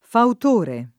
fautore [ faut 1 re ]